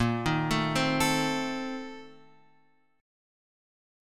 A# Major 7th Suspended 2nd Suspended 4th